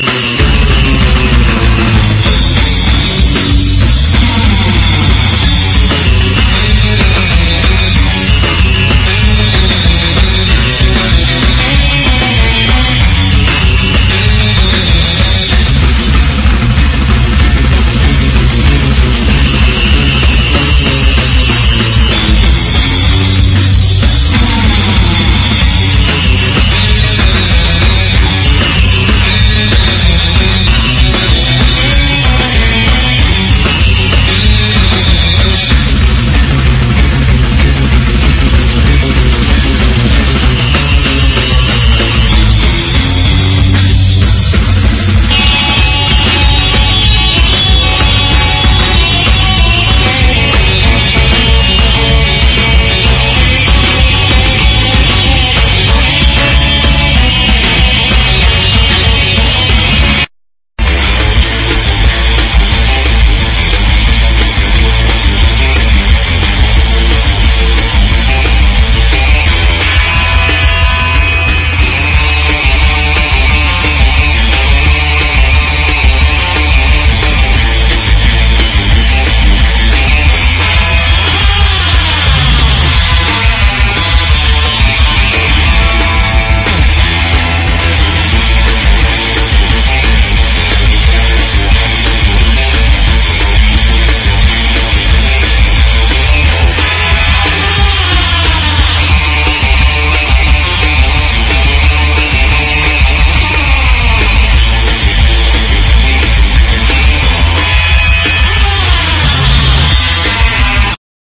胸キュン・ノーザン・ガールポップ